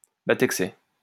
Battexey (French pronunciation: [batɛksɛ]